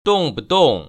[dòng‧budòng] 똥부동